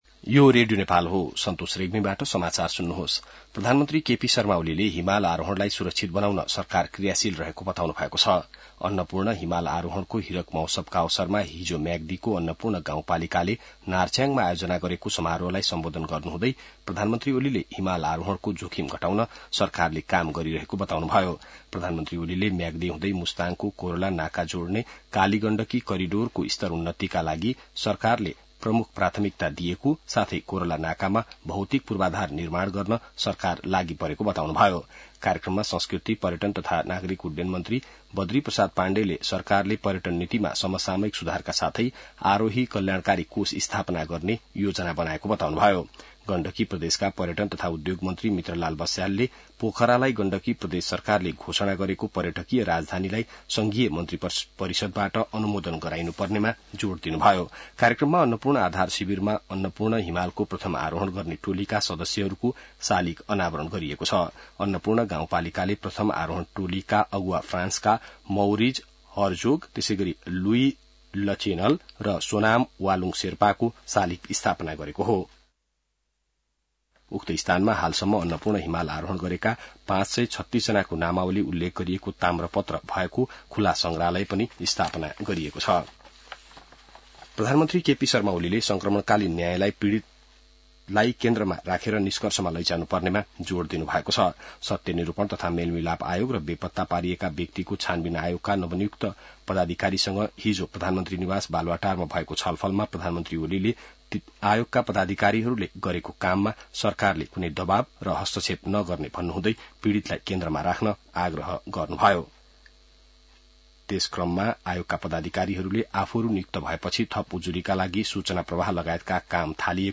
An online outlet of Nepal's national radio broadcaster
बिहान ६ बजेको नेपाली समाचार : २१ जेठ , २०८२